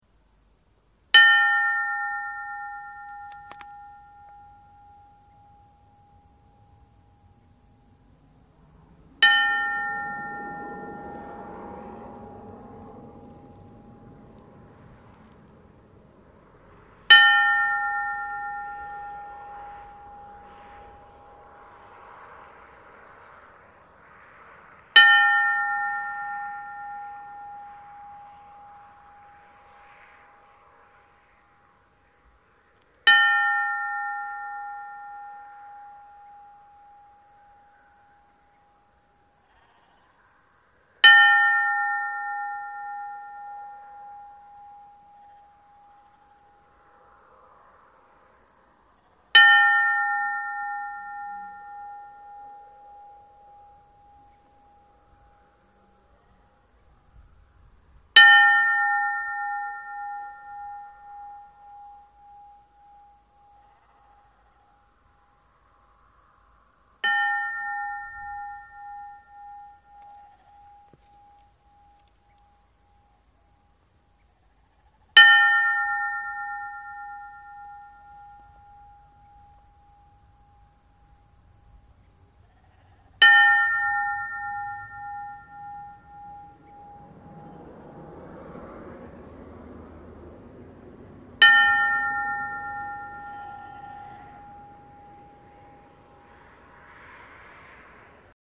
Í Stykkishólmskirkju eru fjórar kirkjuklukkur sem voru vígðar á aðfangadag jóla 1994.
stykkisholmskirkja_saluhlid.mp3